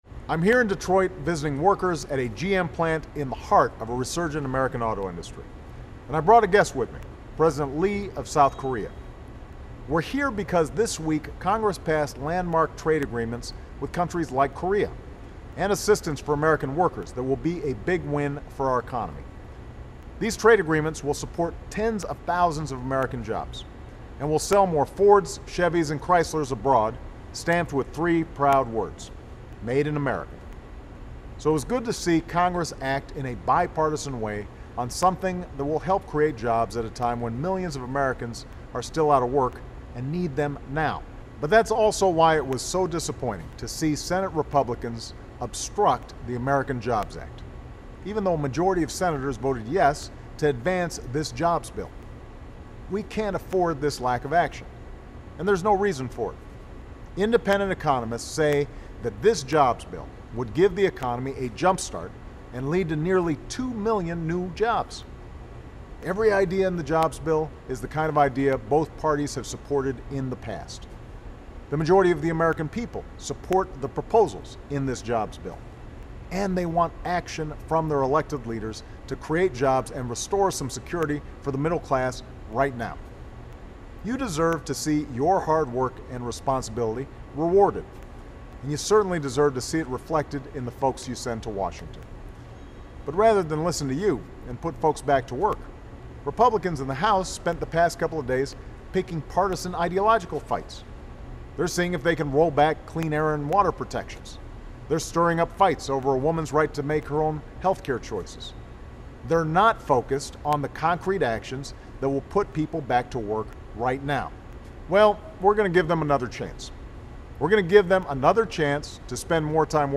From a GM plant in Detroit, President Obama highlights the landmark trade agreements passed this week which will support tens of thousands of American jobs, level the playing field for American workers, and help us meet our goal of doubling our exports.
Remarks of President Barack Obama